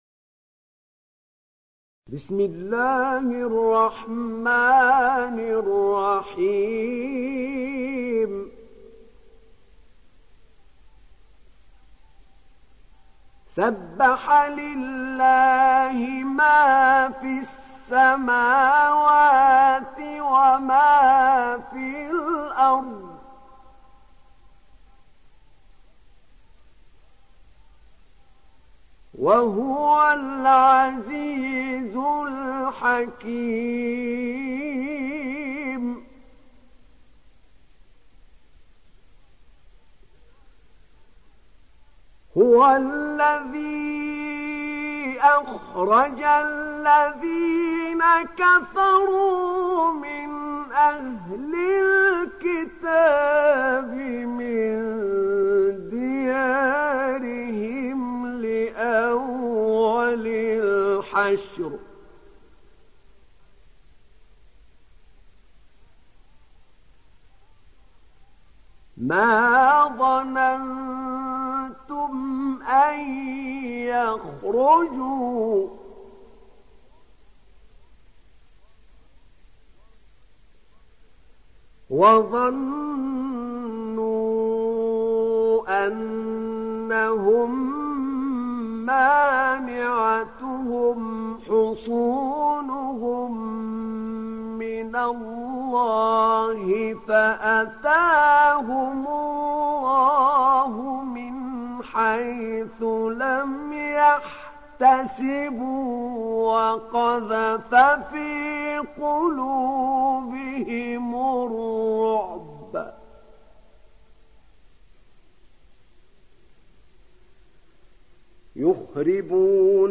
تحميل سورة الحشر mp3 بصوت أحمد نعينع برواية حفص عن عاصم, تحميل استماع القرآن الكريم على الجوال mp3 كاملا بروابط مباشرة وسريعة